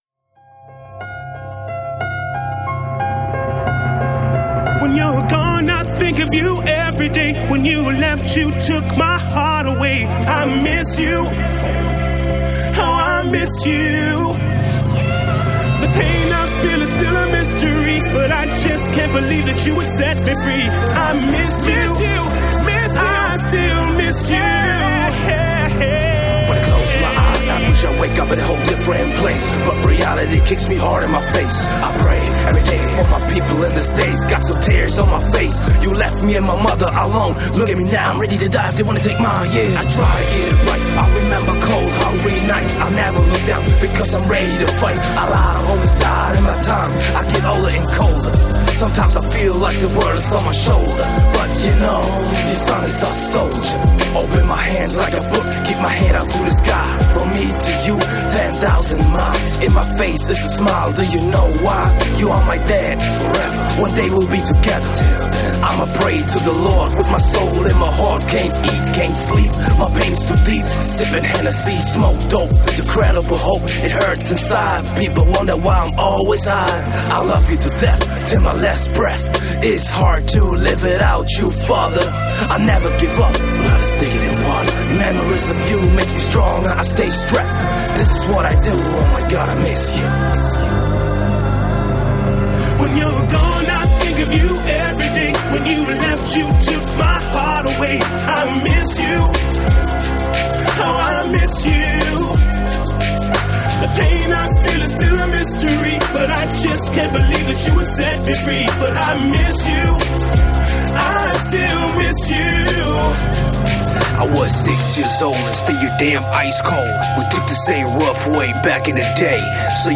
Жанр: Rap, Hip-Hop